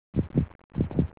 heartbeat.au